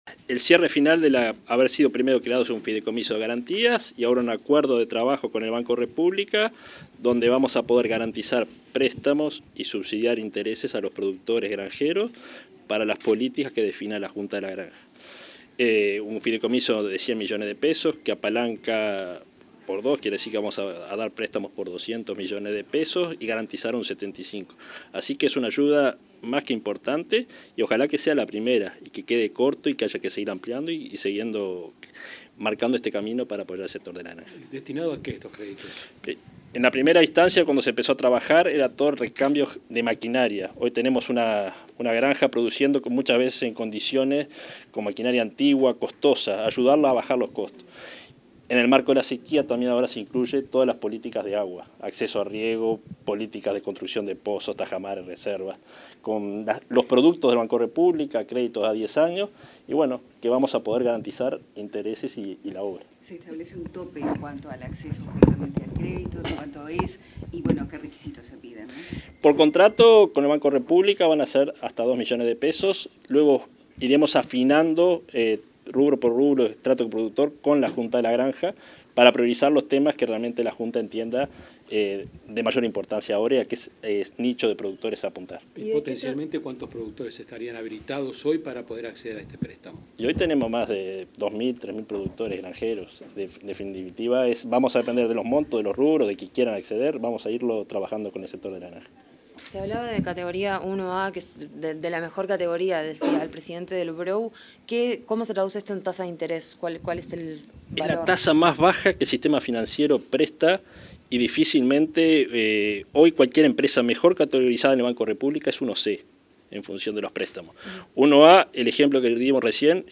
Declaraciones del director general de la Granja, Nicolás Chiesa